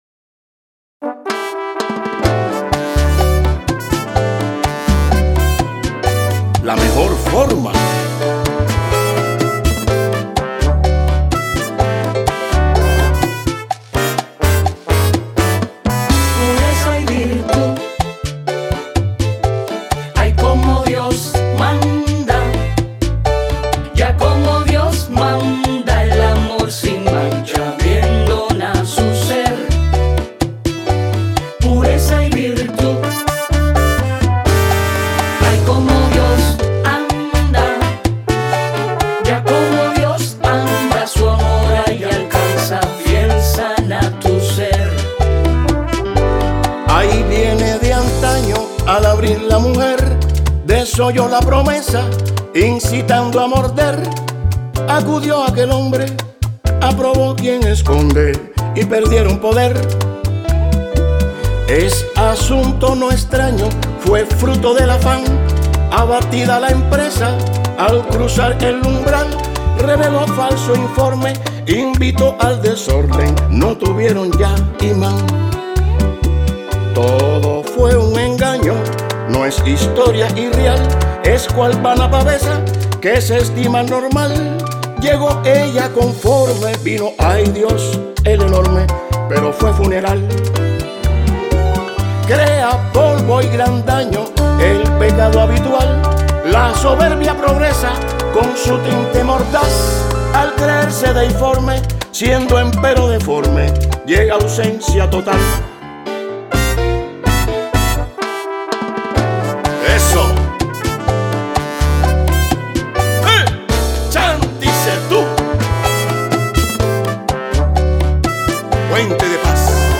Una vez la letra nueva y su ritmo alegre de cha cha chá se asentaron en mí, noté claramente que, aunque la composición podría ser útil para las bellas destinatarias, ella era realmente más requerida por mí, para que mi amor hacia ellas fuera de veras fructífero, para que acaso fuera menos moralista y más coherente en su acción, de modo que, al aumentar mi propia pureza y virtud, pudiera intentar enseñar el amor desde el dificultoso arte del ejemplo.
Como se puede notar, la canción — excluyendo sus alegres y proactivos coros — consta de dos conjuntos de cuatro estrofas, cada una compuesta por siete líneas.